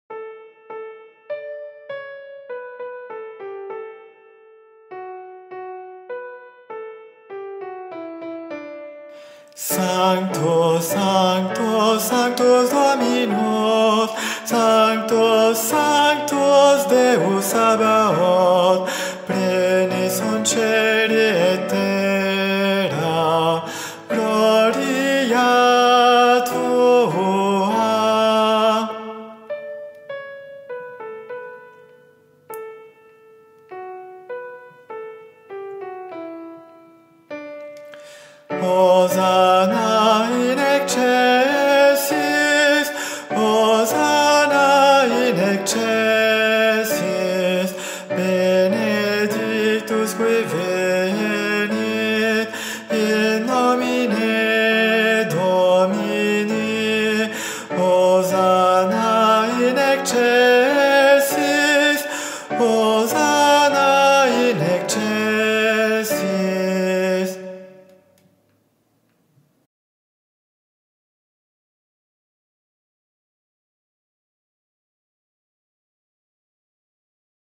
R�p�tition de la pi�ce musicale N� 519
Missa Pro Europa - Sanctus - Guide voix - Ténors.mp3